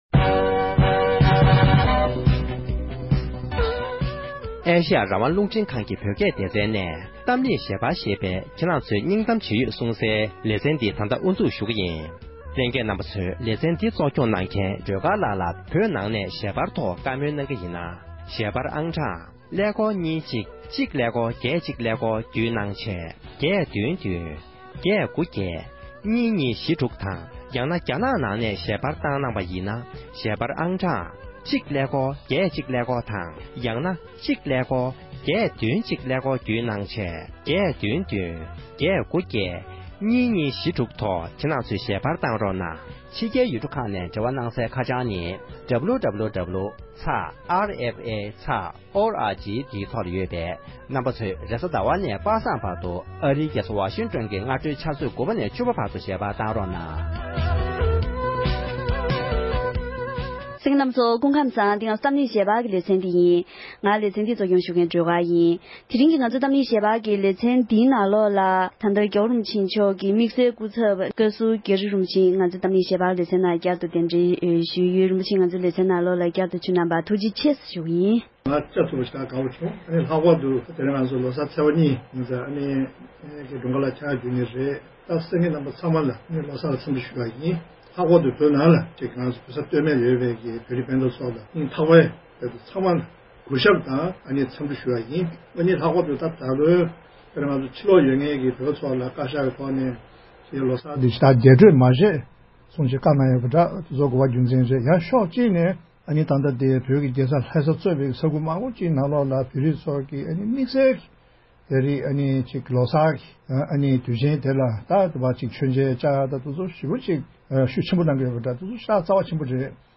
༸གོང་ས་མཆོག་གི་དམིཌ་བསལ་སྐུ་ཚབ་རྒྱ་རི་རིན་པོ་ཆེ་མཆོག་གཏམ་གླེང་ཞལ་པར་གྱི་ལེ་ཚན་ཐོག་གློང་མོལ་གནང་།